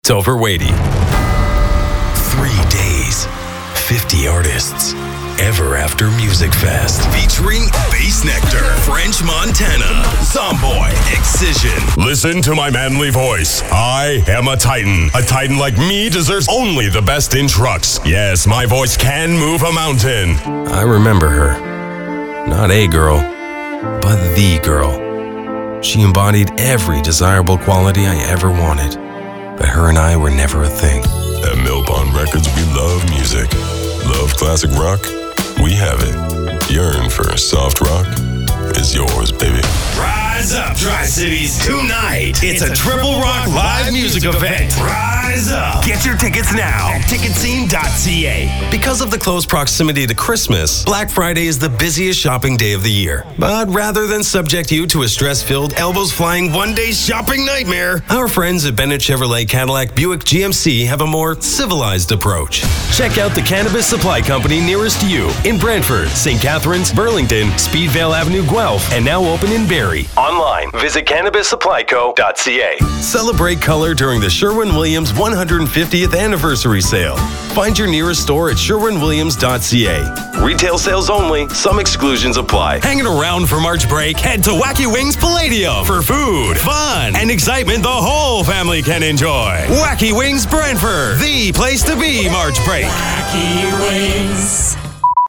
Adult, Mature Adult
Has Own Studio
• Accent: North American English, some Canadian French
• Voice Descriptions: Deep, booming, authoritative, announcer, warm & friendly, sexy